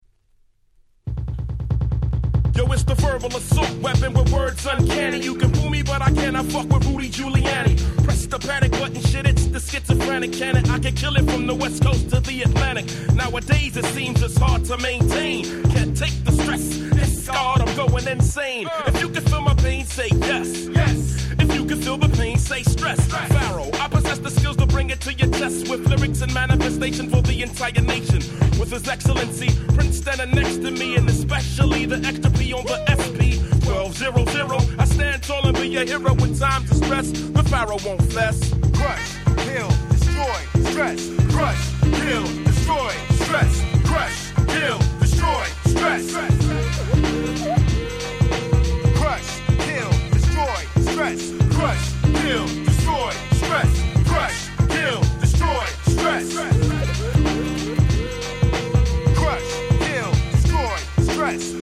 94' Smash Hit Hip Hop !!